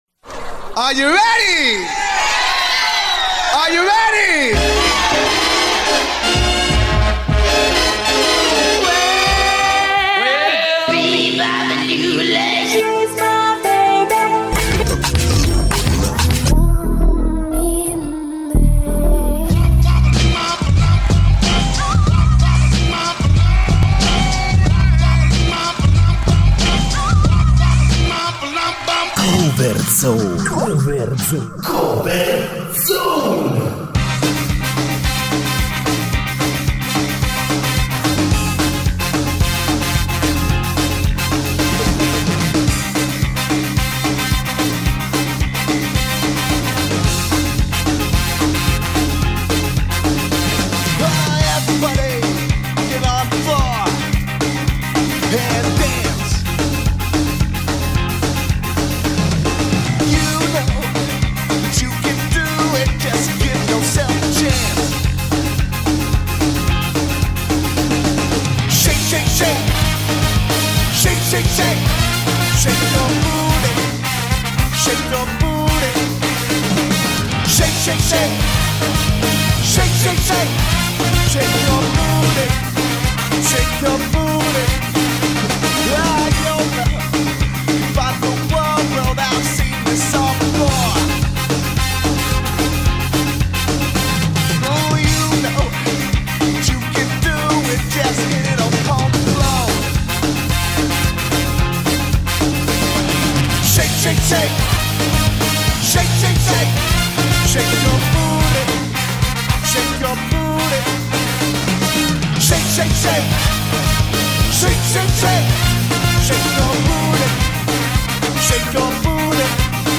gente senza ‘na lira che smanetta appunto lo strumento per poveracci per antonomasia